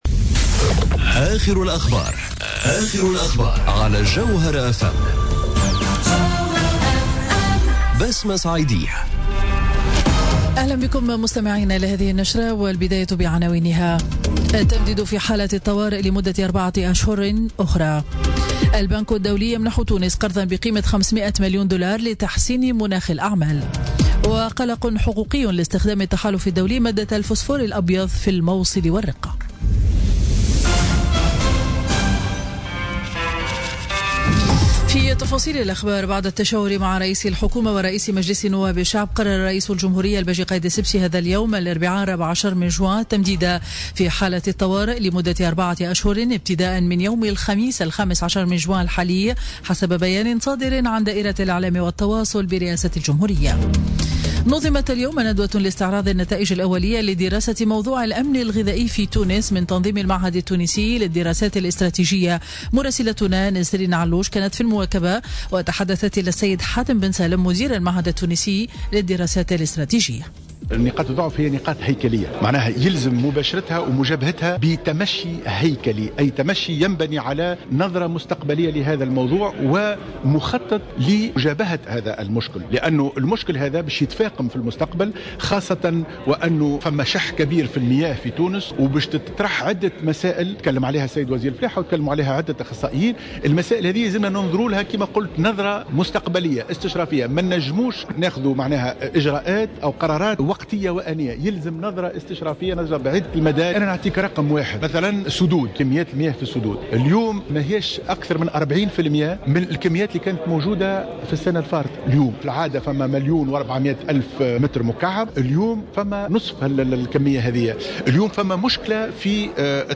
نشرة أخبار منتصف النهار ليوم الإربعاء 14 جوان 2017